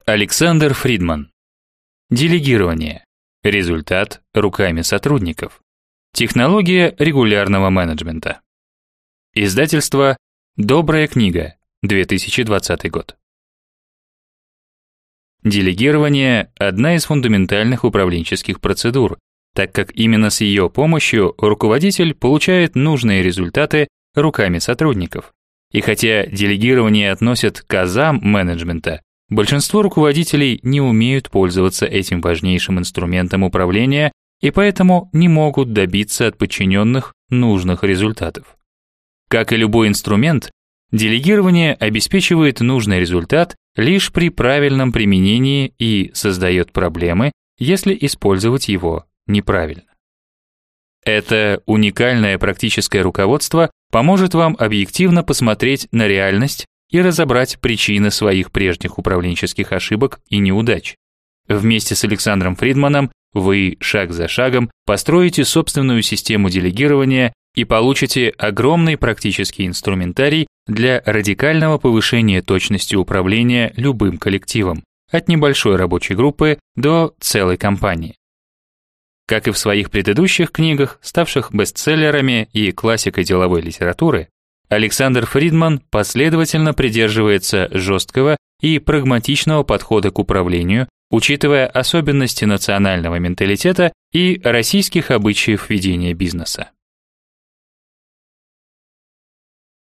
Аудиокнига Делегирование: результат руками сотрудников. Технология регулярного менеджмента | Библиотека аудиокниг